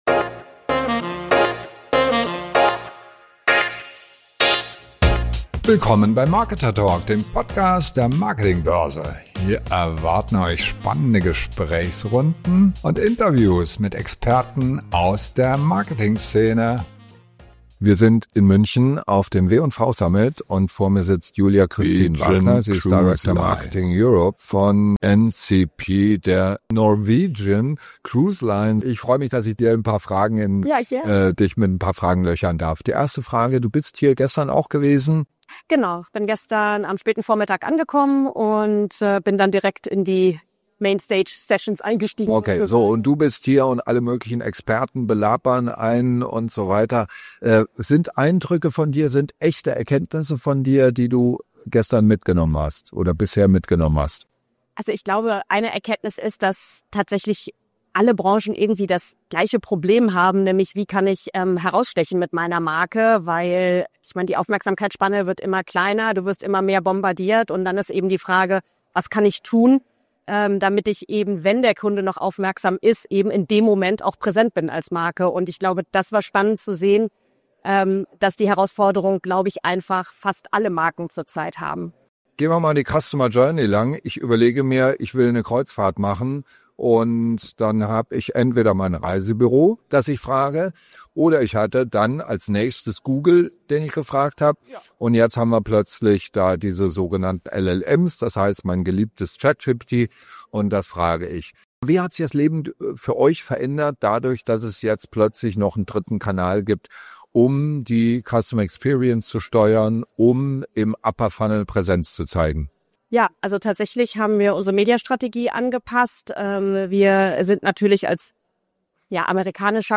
Wie schaffst du es, deine Marke noch sichtbar zu machen, wenn alle die gleichen Probleme haben? Im Interview erfahrst du, wie Norwegian Cruise Line mit einer strategischen Umverteilung der Marketing-Budgets die Aufmerksamkeit zurückgewinnt – und was LLMs dabei verändern.